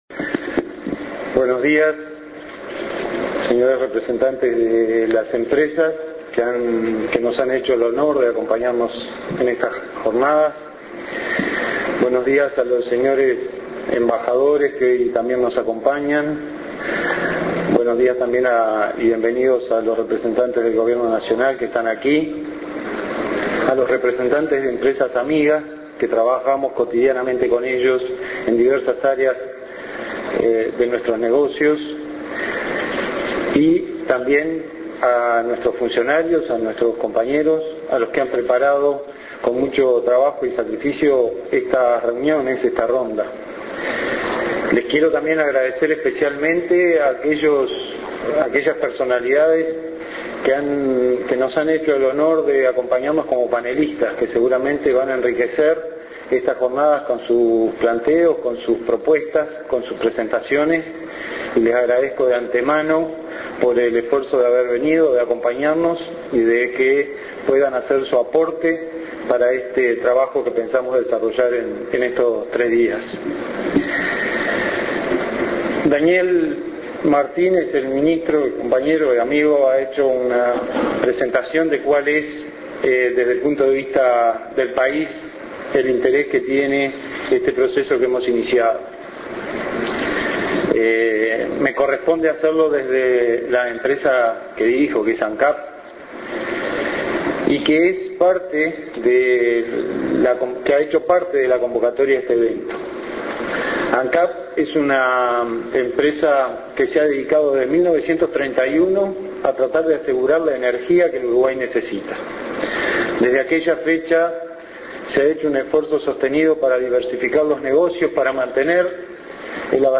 Palabras de Ra�l Sendic Escuchar MP3 Ver video (WMV)